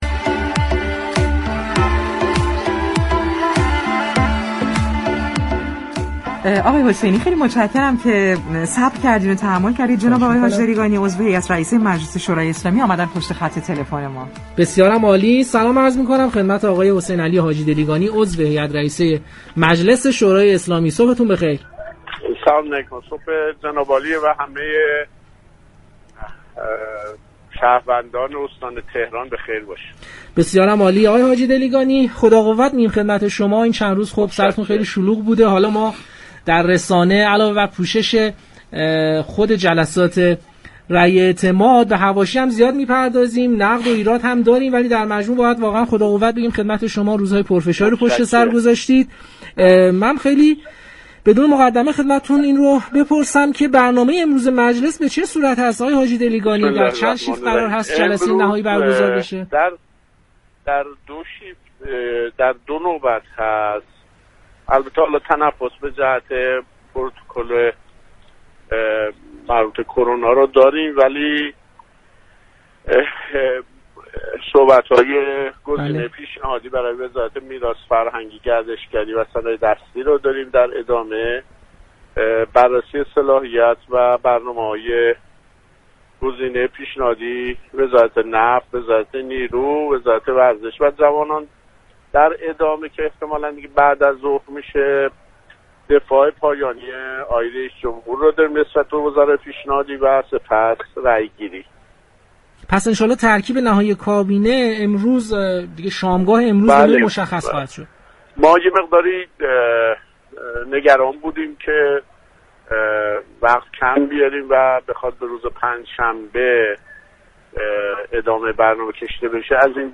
به گزارش پایگاه اطلاع رسانی رادیو تهران، حسینعلی حاجی‌دلیگانی، عضو هیات رئیسه مجلس شورای اسلامی در گفتگو با برنامه پارك شهر رادیو تهران، درباره برنامه امروز چهارشنبه 3 شهریور مجلس برای رأی اعتماد وزاری پیشنهادی دولت سیزدهم گفت: امروز در دو نوبت صبح و بعدازظهر مجلس صلاحیت وزار را بررسی می‌كند.